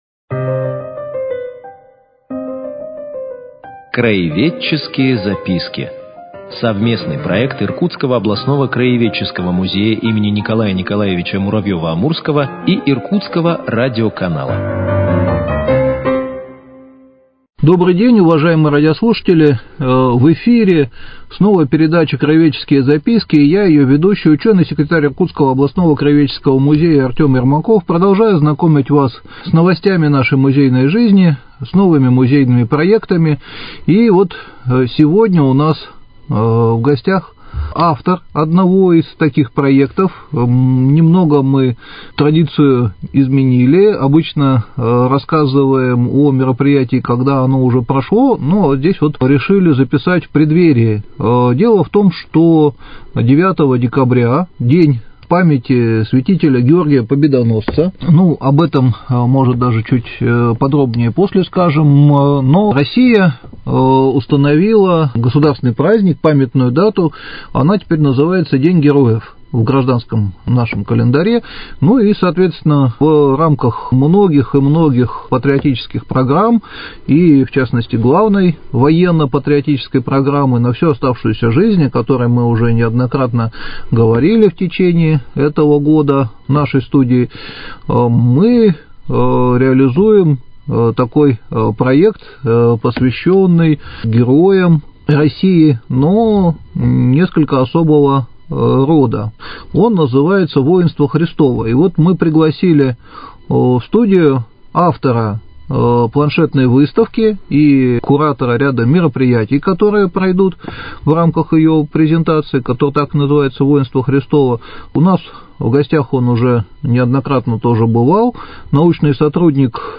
Цикл передач – совместный проект Иркутского радиоканала и Иркутского областного краеведческого музея им. Н.Н.Муравьёва - Амурского.